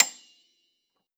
53q-pno29-A6.aif